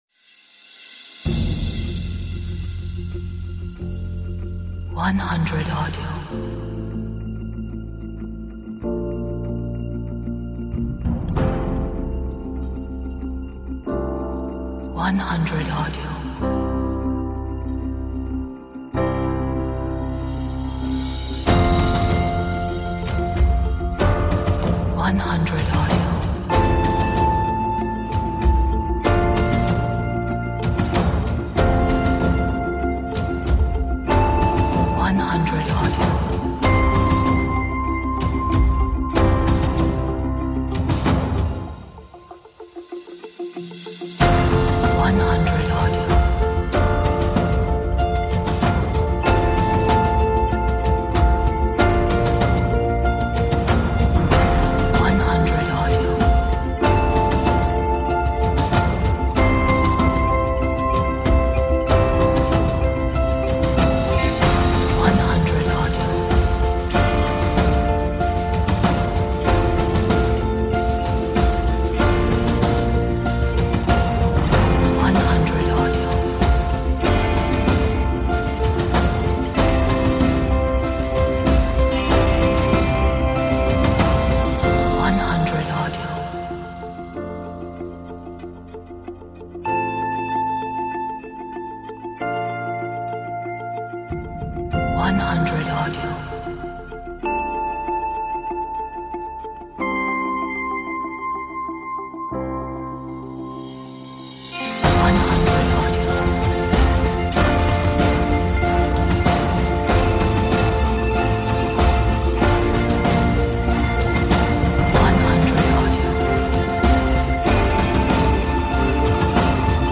an uplifting and positive track that builds up